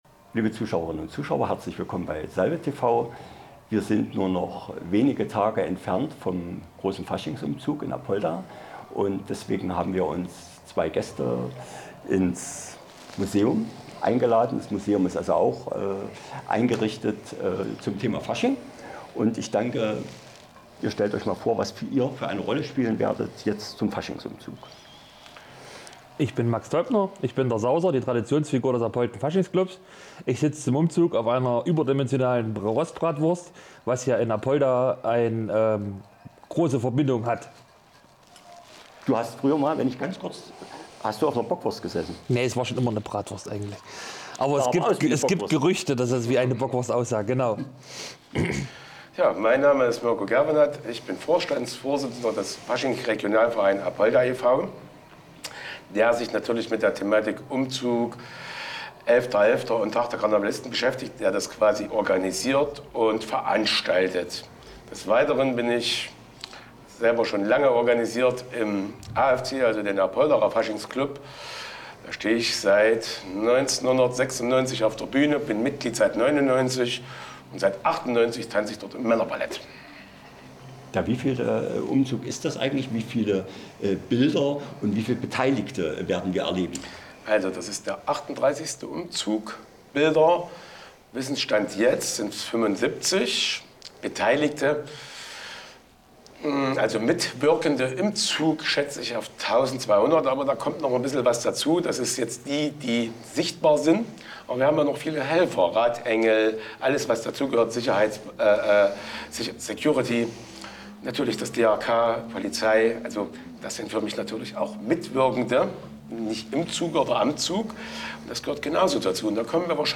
Der Startschuss f�r die Vorbereitungen auf das 6. Elefantenfest 2032 ist f�r die �Kitzelbacher� bereits gefallen. Die beiden Kommunalpolitiker berichten, was es mit diesem Gro�ereignis auf sich hat und wie sich vor allem die Vereine daf�r engagieren.